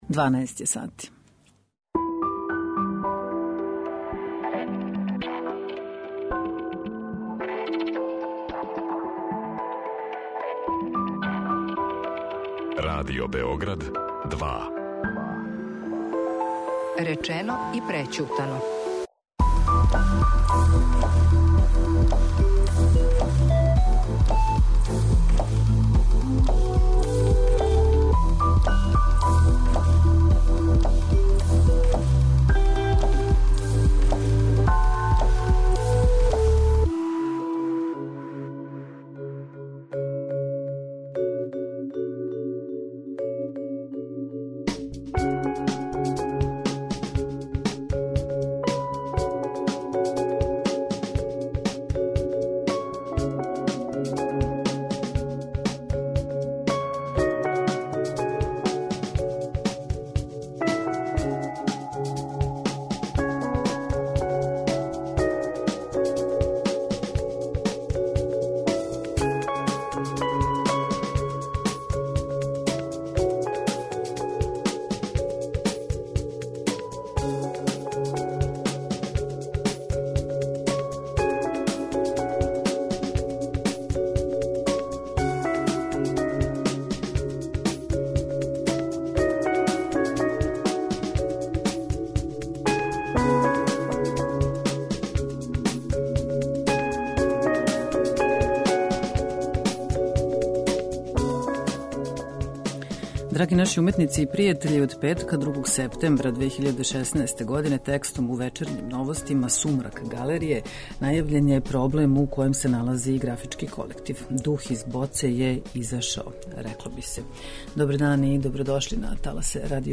Трагом вести о простору у Карађорђевој улици који је могао да представља решење за Галерију Графички колектив, у данашњој емисији дискутујемо о галеријским просторима и просторима за културу у граду.